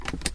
描述：汽车安全带紧固
Tag: 点击 安全带